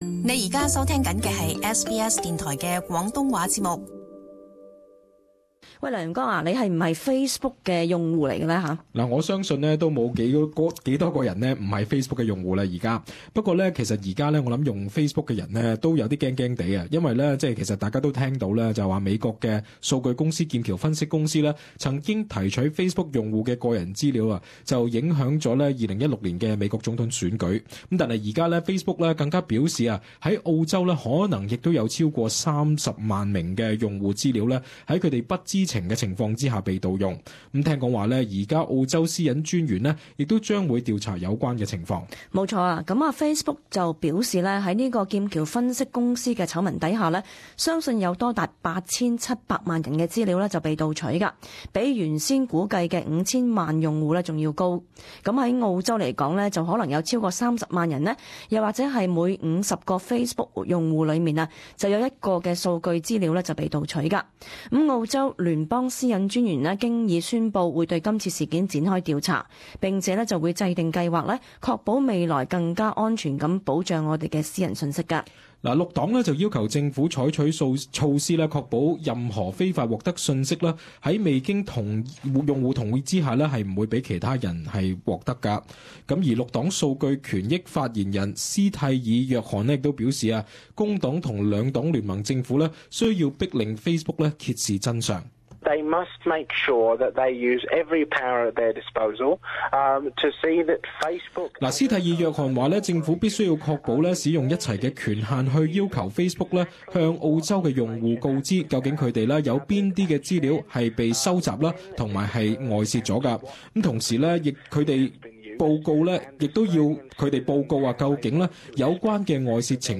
【時事報導】 30 萬澳人 Facebook 資料被盜